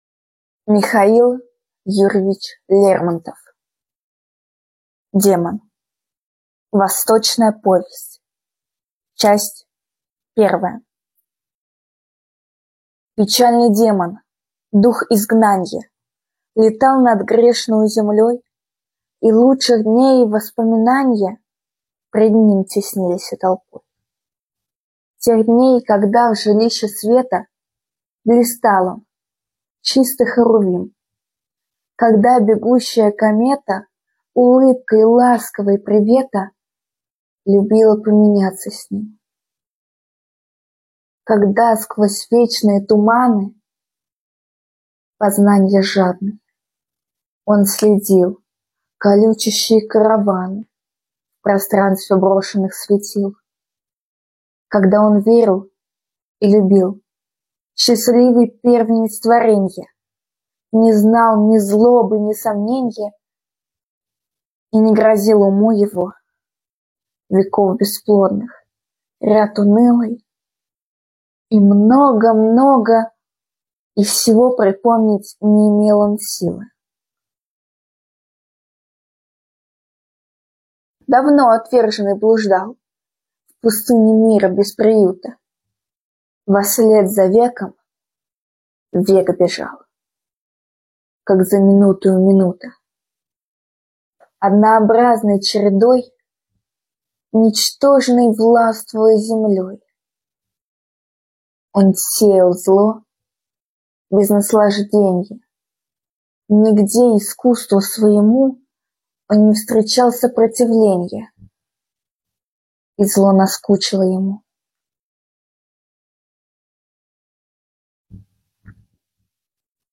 В рамках проекта «Волонтеры читают» мы озвучили эту поэму!